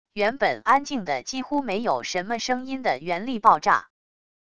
原本安静得几乎没有什么声音的原力爆炸wav音频